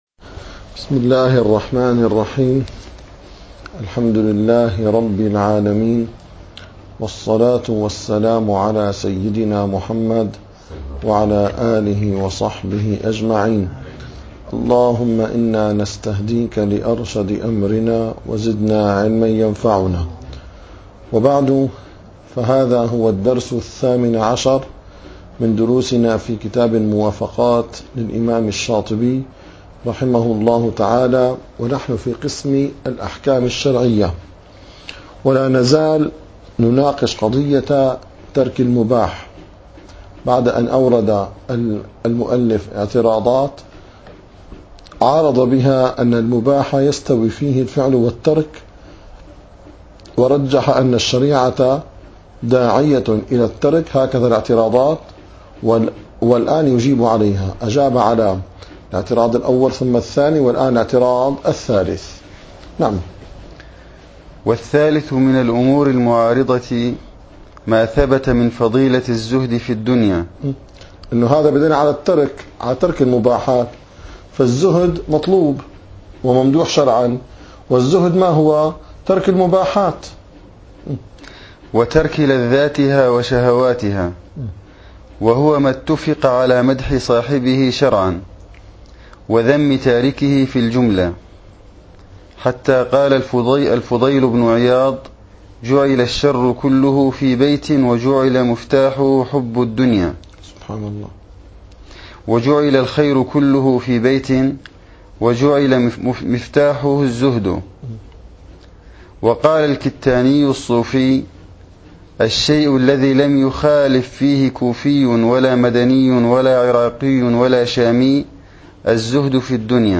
- الدروس العلمية - شرح كتاب الموافقات للشاطبي - 18- الثالث ماثبت من فضيلة الزهد